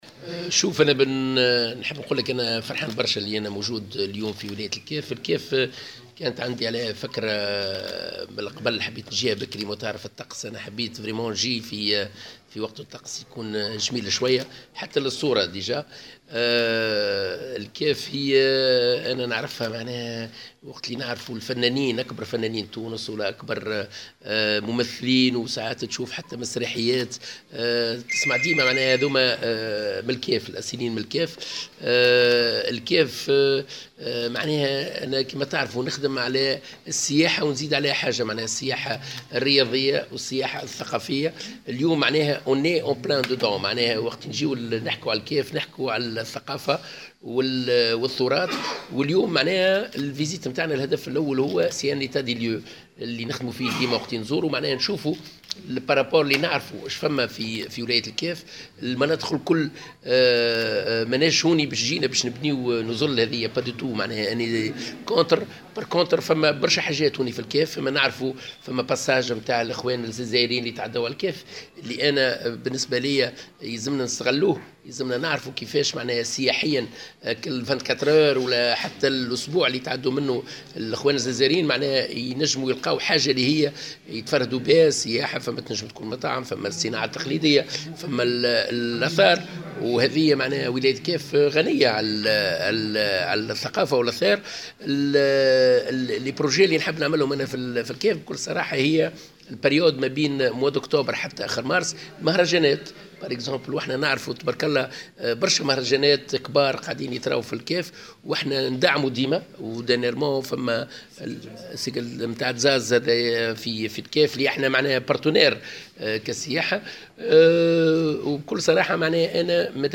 وقال الطرابلسي، في تصريح لمراسل الجوهرة أف أم، لدى إشرافه اليوْم الأربعاء، على الدورة الاستثنائية للمجلس الجهوي للسياحة بالكاف، إن الكاف قادرة على الانتفاع بعبور السياح الجزائريين عبرها، من أجل ترويج منتوجاتها من الصناعات التقليدية وخدماتها الثقافية بفضل ما تزخر به من مواقع أثرية.